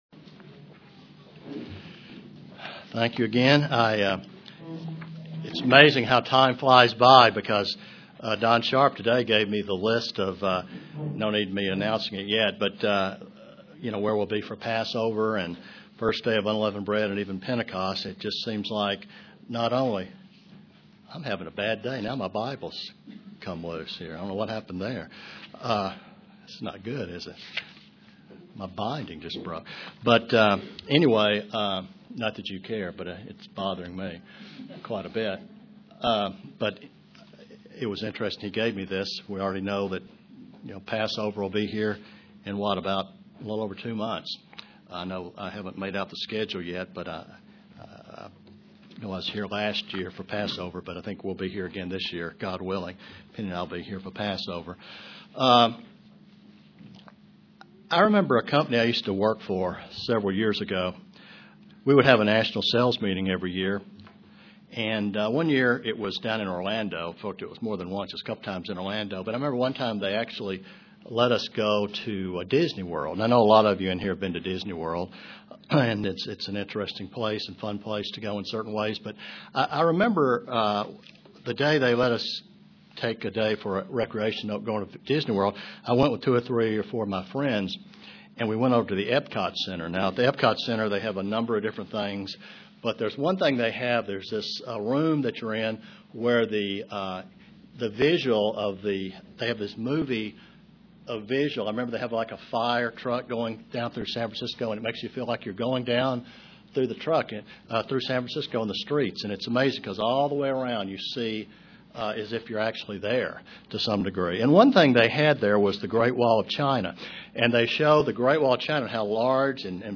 Print The life of a Christian is to grow in Godly character UCG Sermon Studying the bible?